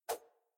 throw2.ogg